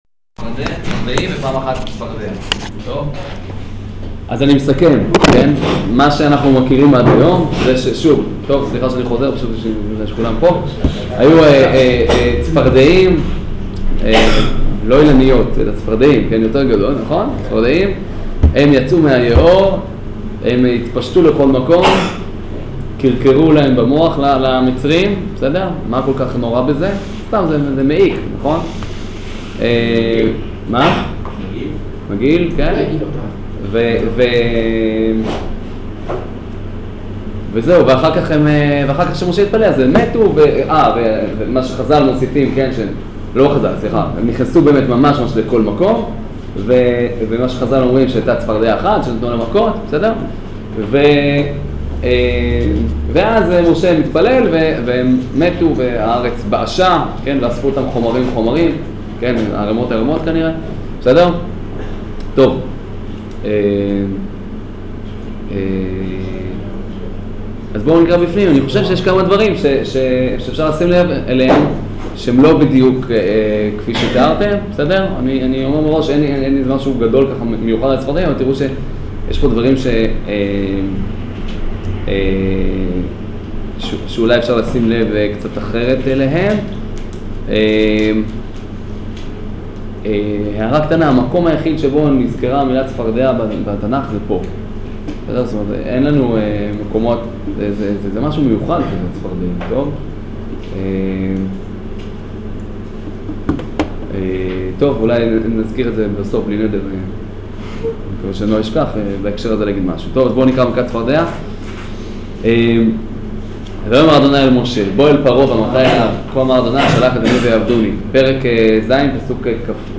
שיעור פרשת וארא